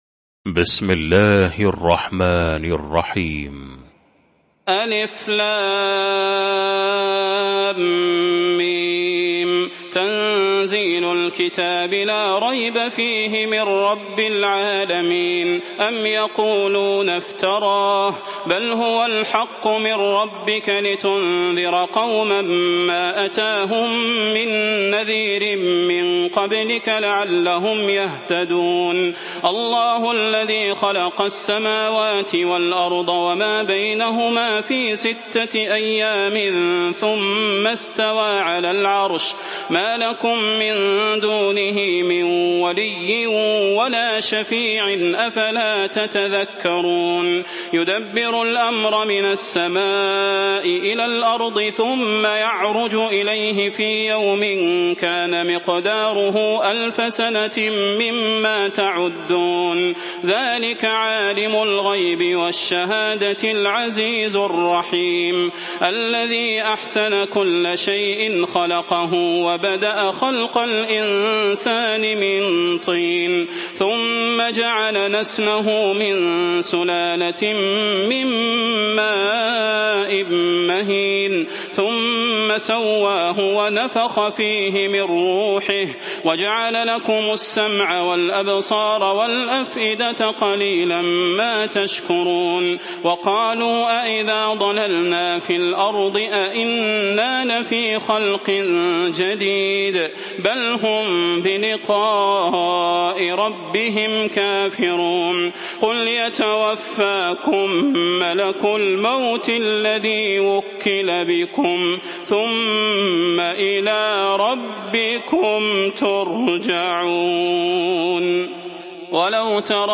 المكان: المسجد النبوي الشيخ: فضيلة الشيخ د. صلاح بن محمد البدير فضيلة الشيخ د. صلاح بن محمد البدير السجدة